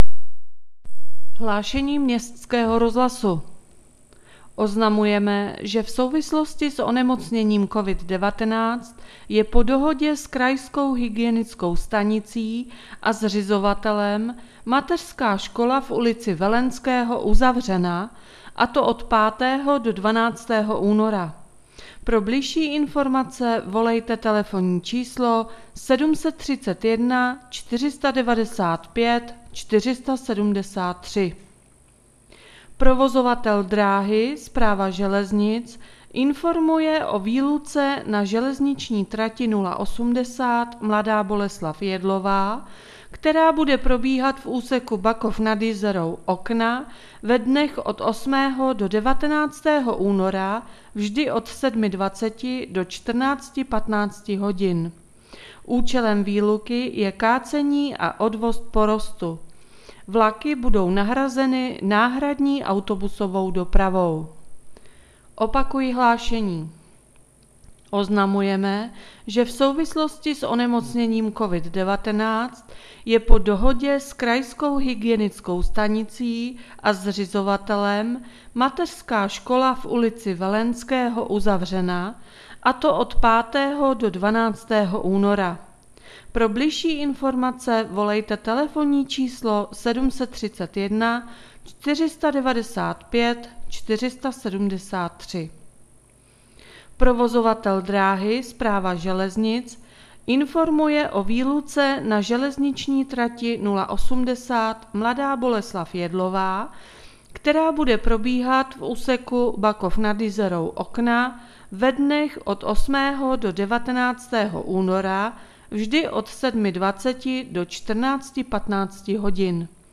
Hlášení městského rozhlasu 5.2.2021